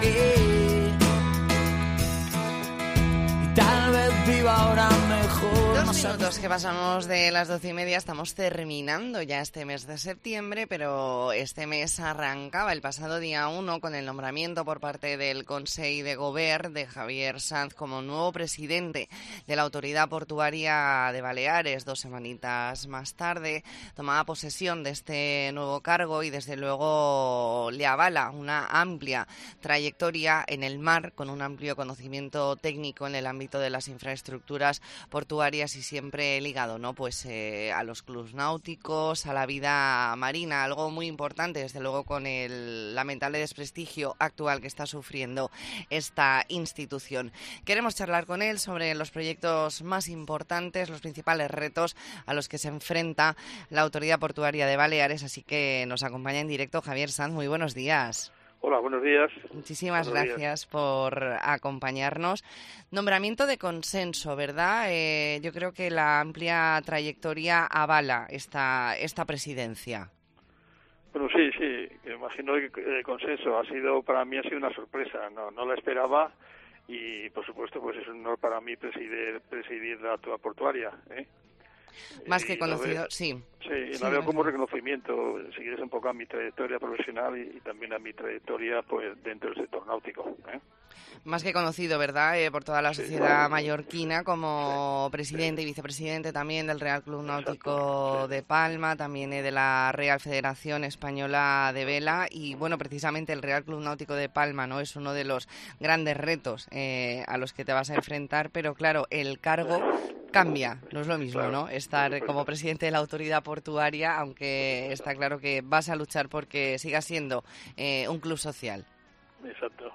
E ntrevista en La Mañana en COPE Más Mallorca, viernes 29 de septiembre de 2023.